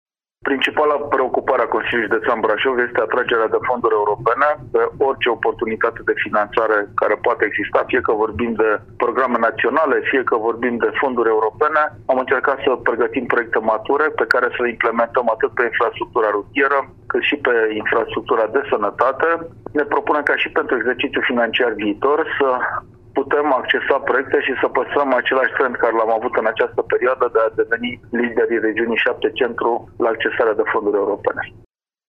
La întâlnirea Comitetului Regional pentru elaborarea Planului de Dezvoltare Regională Centru,  preşedintele Consiliului Judeţean Braşov, Adrian Veştea a precizat că încearcă să atragă cât mai multe fonduri europene pentru dezvoltarea judeţului: